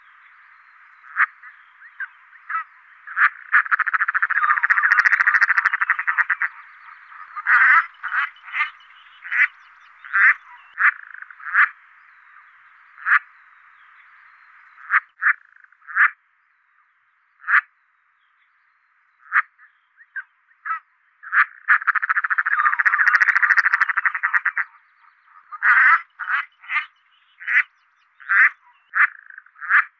tadorne-de-belon.mp3